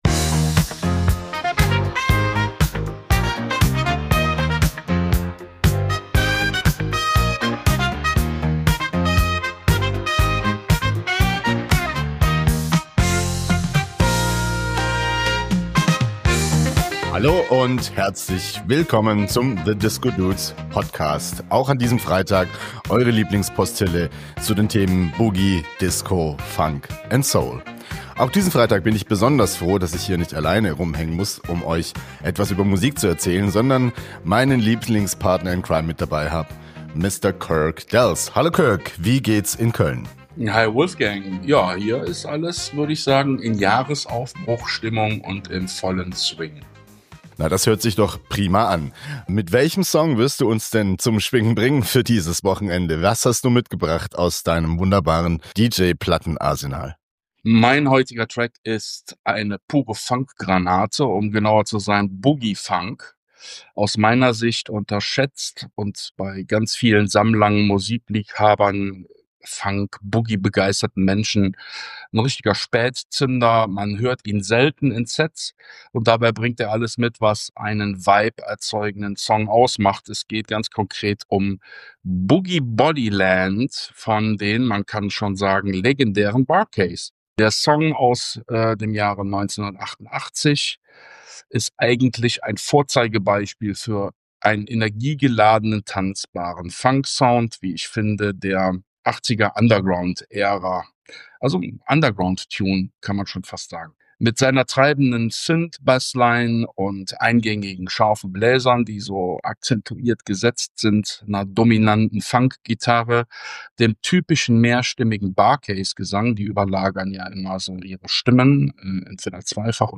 New York City R&B Boogie und ein Funk